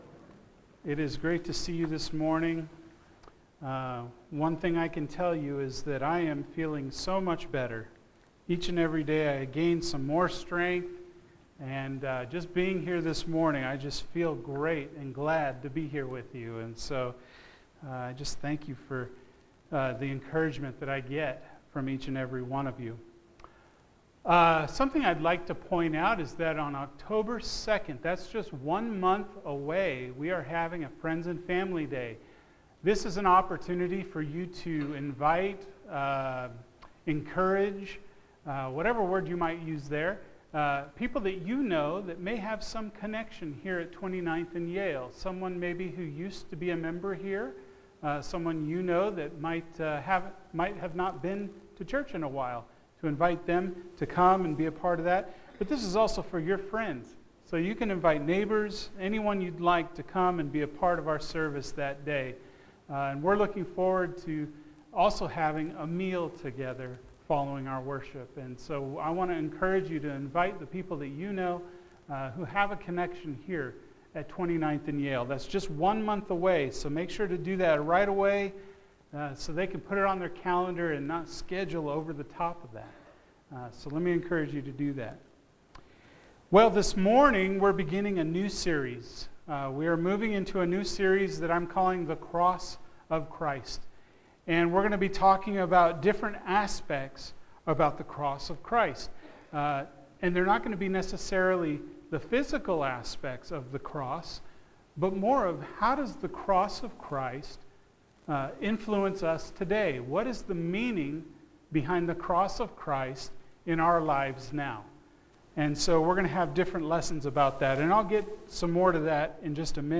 The Cross is a Foolish Message – 1 Corinthians 1:17-31 – Sermon